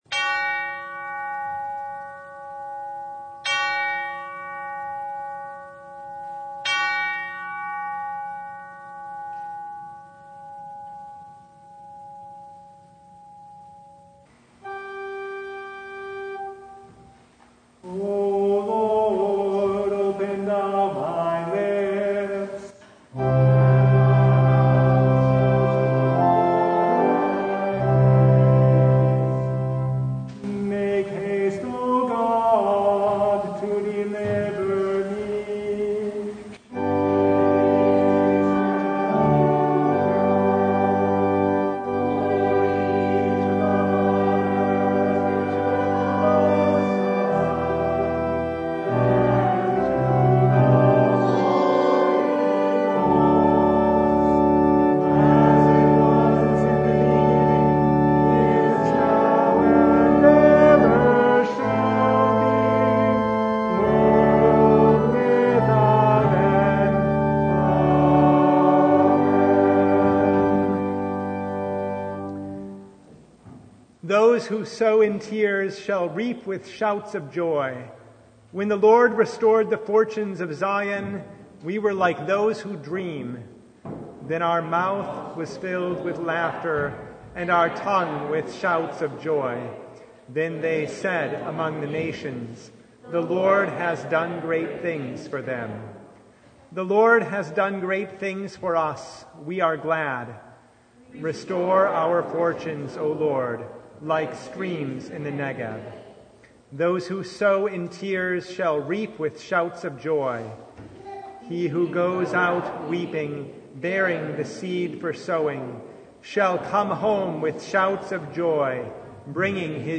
1 Thessalonians 5:16-24 Service Type: Advent Vespers Rejoice always?